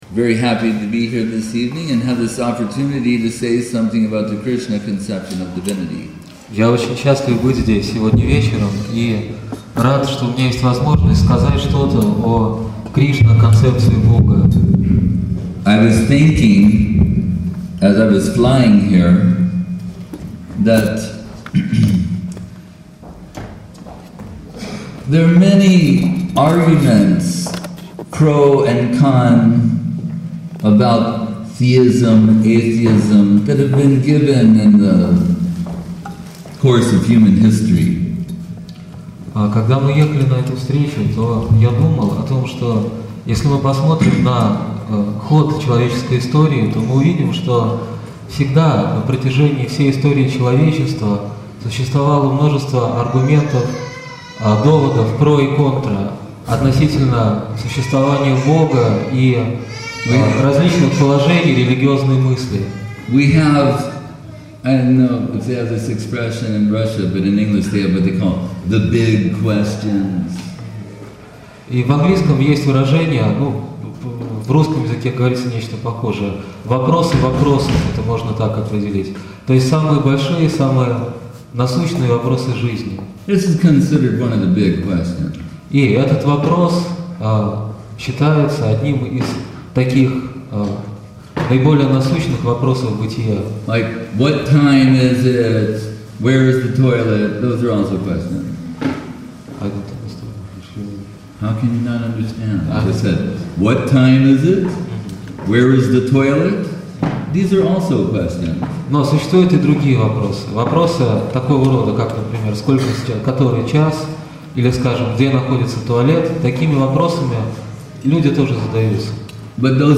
Place: Centre «Sri Chaitanya Saraswati» Moscow